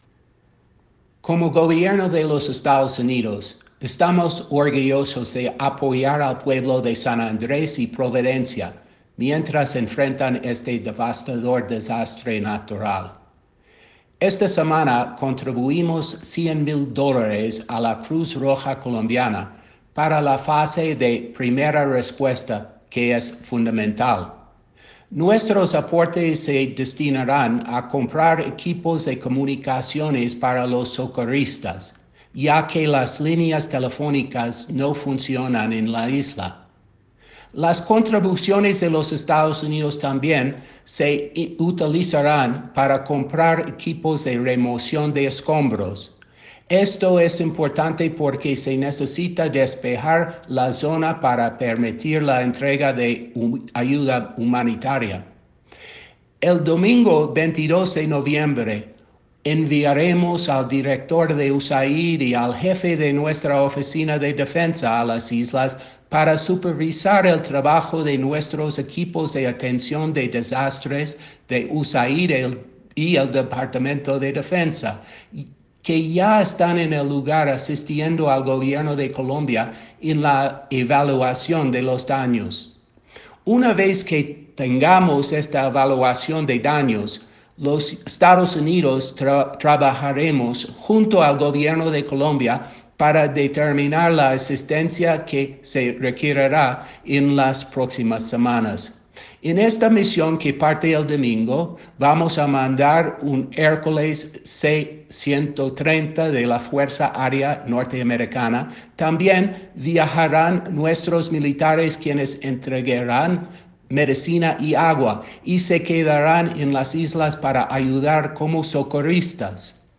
Aclaracion-del-Embajador-de-Estados-UnidosPhilip-S.-Goldberg.m4a